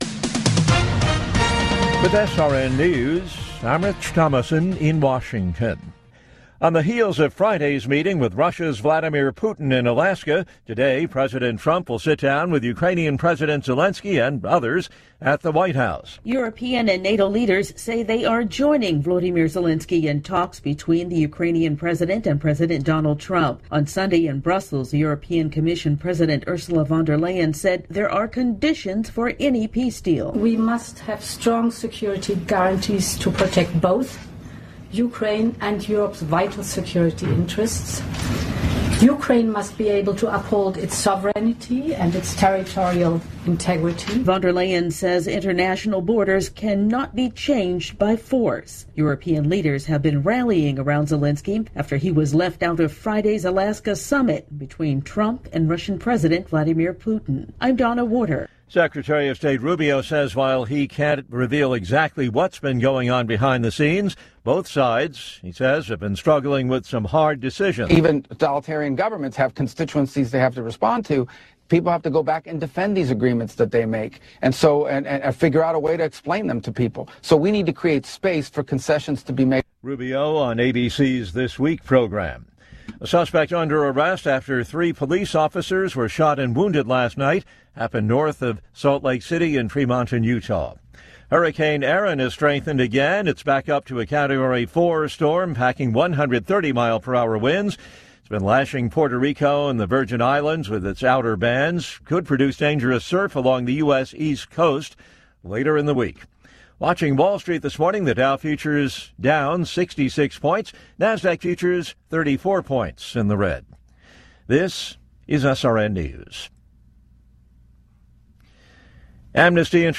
Top News Stories Aug 18, 2025 – 06:00 AM CDT